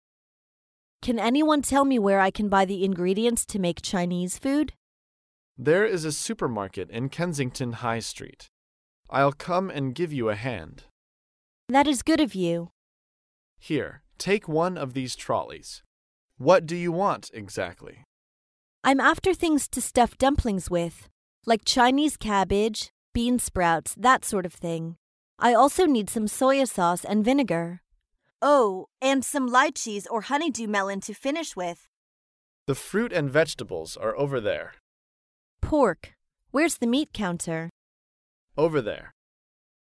在线英语听力室高频英语口语对话 第264期:买蔬菜肉类的听力文件下载,《高频英语口语对话》栏目包含了日常生活中经常使用的英语情景对话，是学习英语口语，能够帮助英语爱好者在听英语对话的过程中，积累英语口语习语知识，提高英语听说水平，并通过栏目中的中英文字幕和音频MP3文件，提高英语语感。